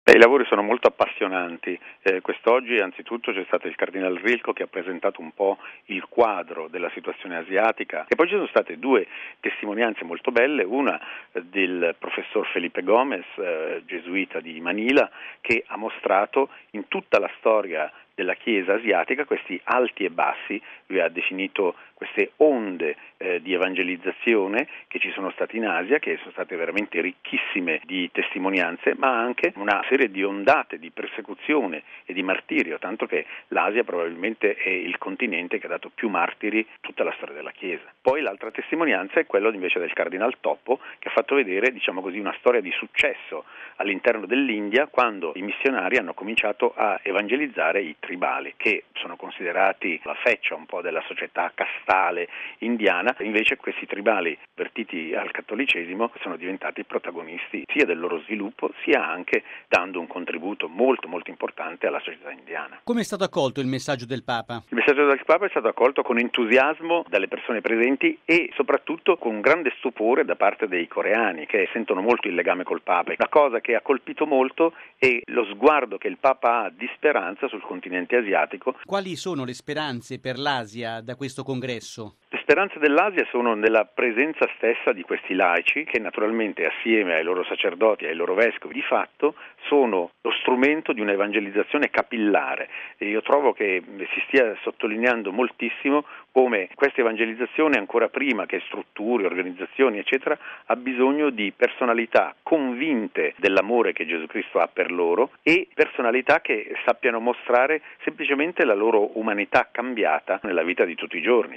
Sulla prima giornata dei lavori a Seoul ascoltiamo padre